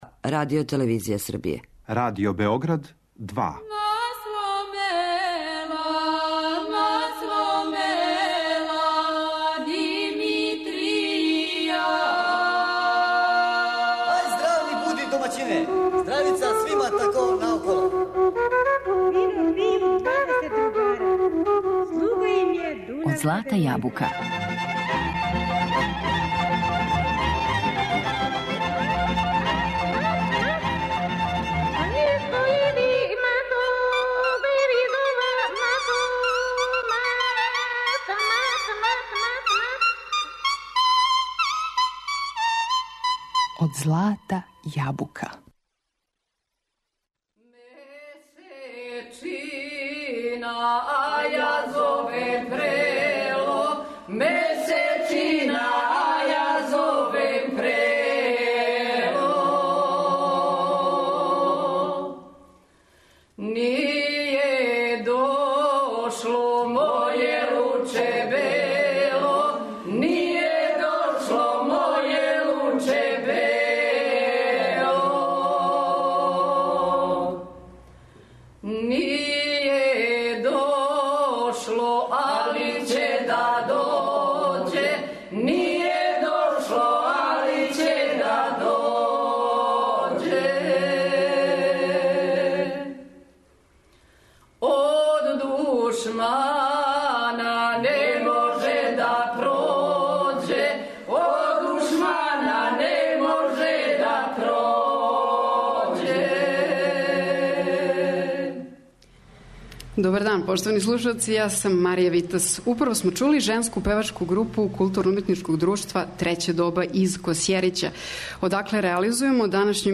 Поводом 90 година Радио Београда, емисију реализујемо уживо из Косјерића.